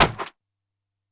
PixelPerfectionCE/assets/minecraft/sounds/item/shield/block4.ogg at mc116